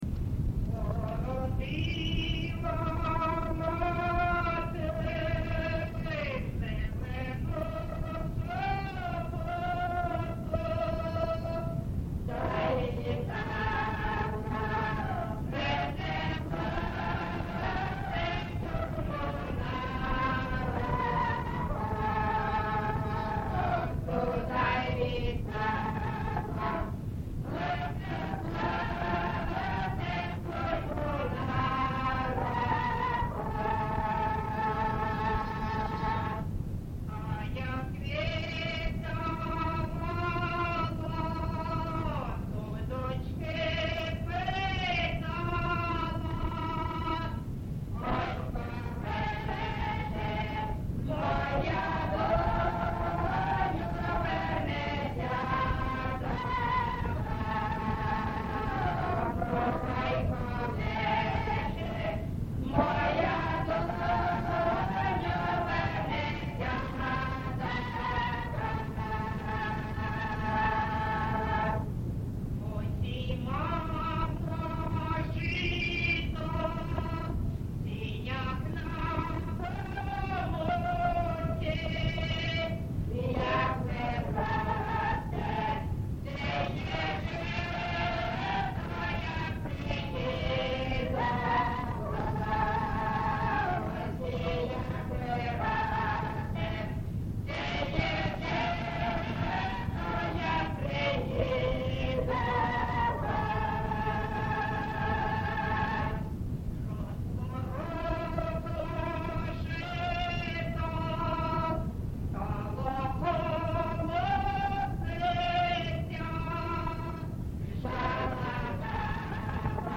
ЖанрПісні з особистого та родинного життя, Строкові
Місце записус. Семенівка, Краматорський район, Донецька обл., Україна, Слобожанщина